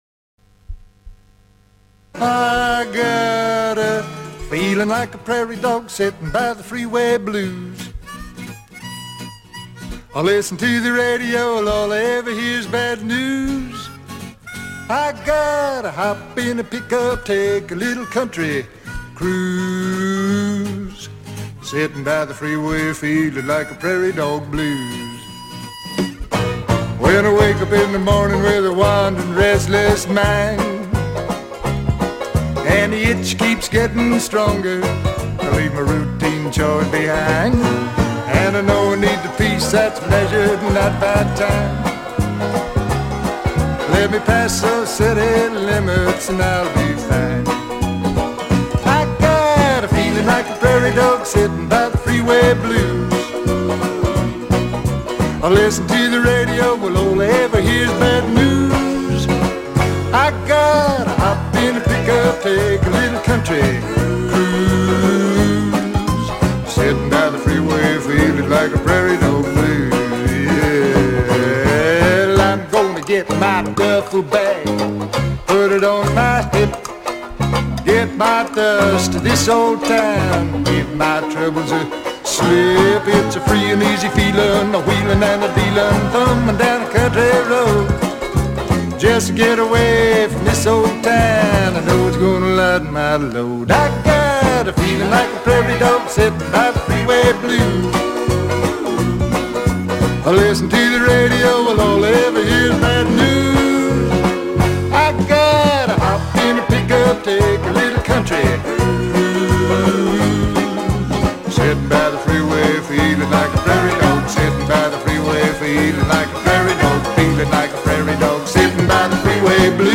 композитором и весьма неплохим исполнителем кантри-песен.